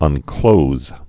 (ŭn-klōz)